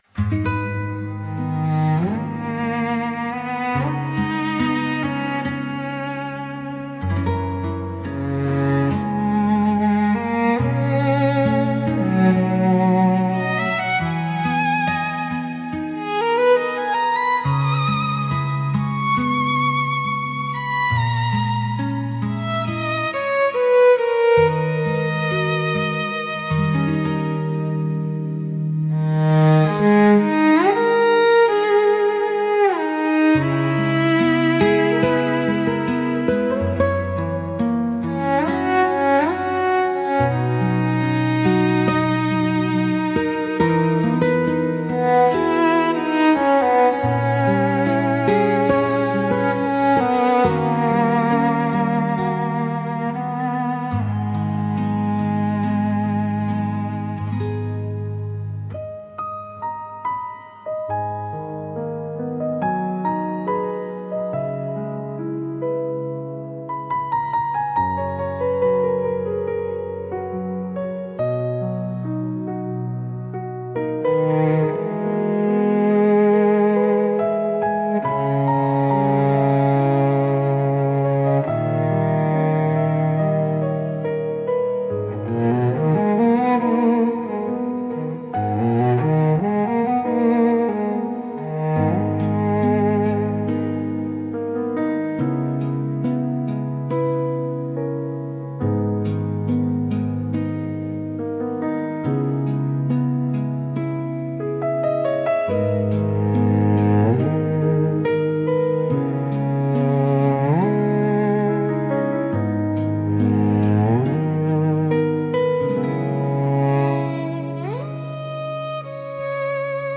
Cello-centered piece for night_03